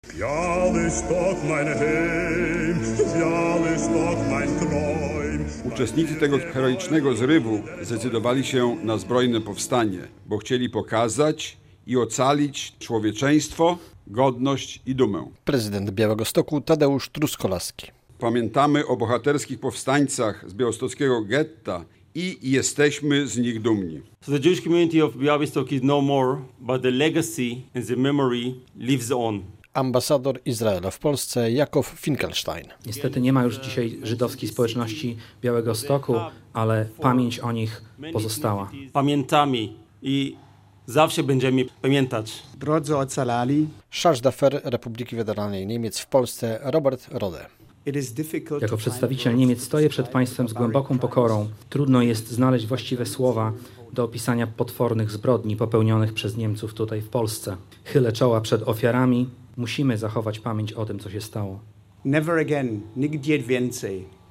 W Białymstoku odbyły się w czwartek (14.08) główne obchody 82. rocznicy wybuchu powstania w miejscowym getcie.
Pamięć, modlitwa i przemówienia